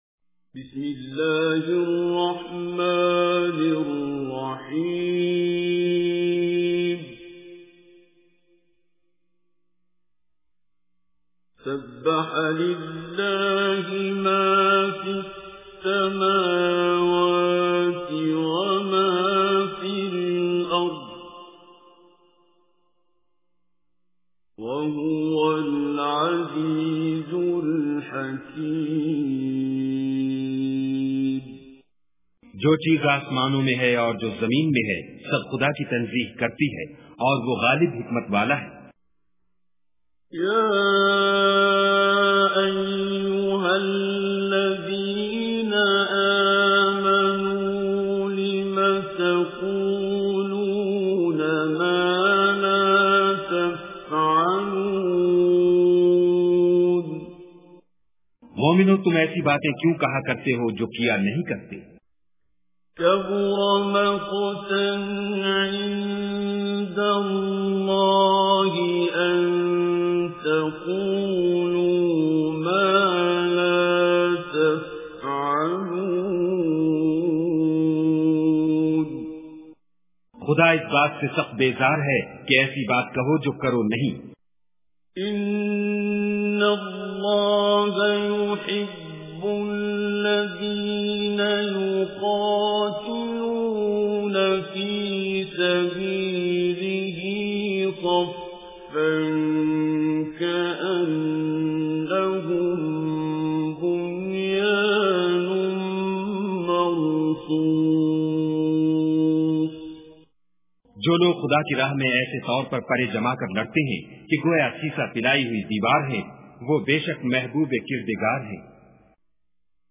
Surah As-Saff Recitation with Urdu Translation
Surah As-Saff is 61 surah of Holy Quran. Listen online and download mp3 tilawat / recitation of Surah As-Saff in the beautiful voice of Qari Abdul Basit As Samad.